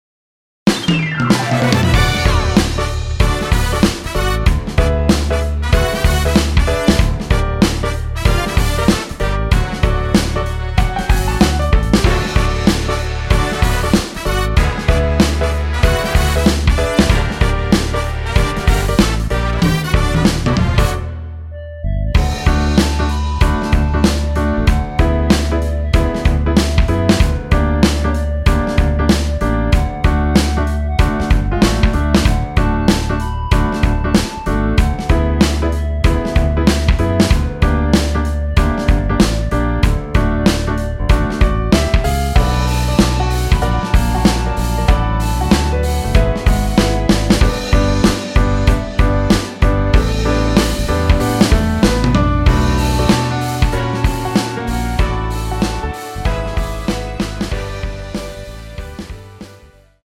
원곡보다 짧은 MR입니다.(아래 재생시간 확인)
원키 (1절앞+후렴)으로 진행되는 멜로디 포함된 MR입니다.
앞부분30초, 뒷부분30초씩 편집해서 올려 드리고 있습니다.
중간에 음이 끈어지고 다시 나오는 이유는